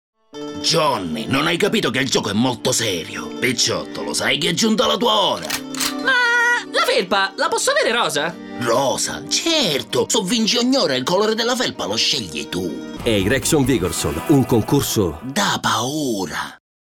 Mafioso